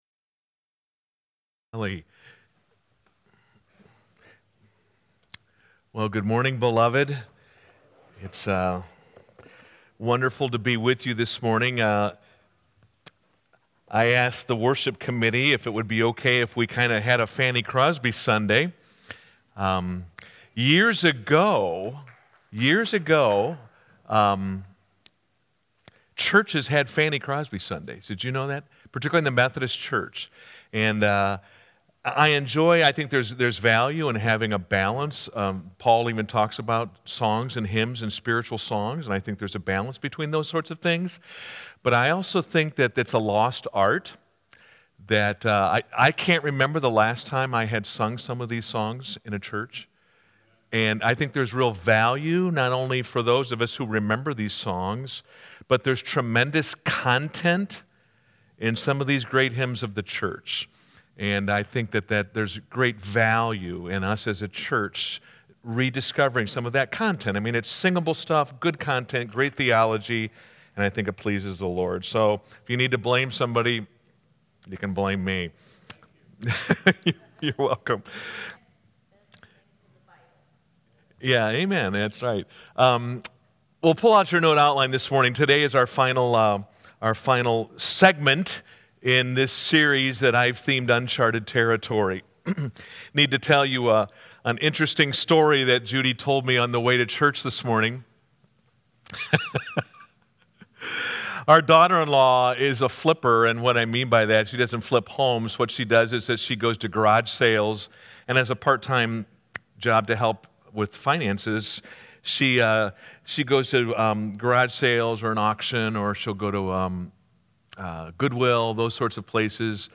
Sermons | California Road Missionary Church